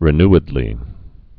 (rĭ-nĭd-lē, -ny-)